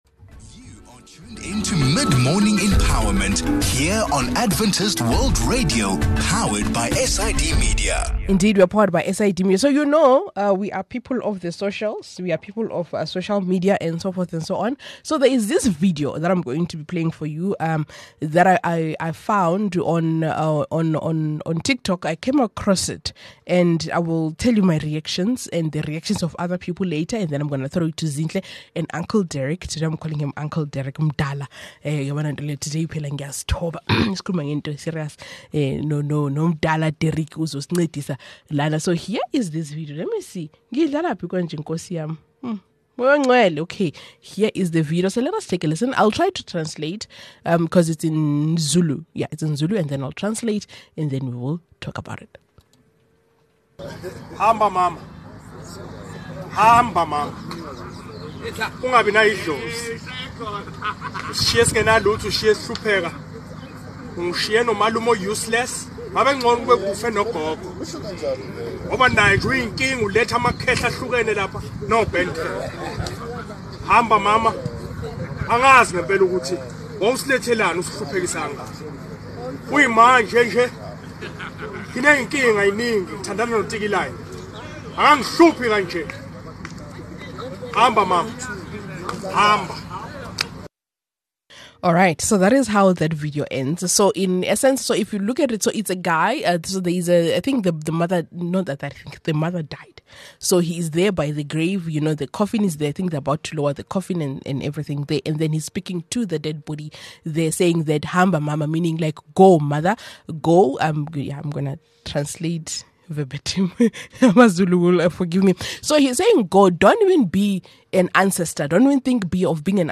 A conversation about whether children has the space to speak freely to their parents. How some conversation open deep wounds and how to heal from that.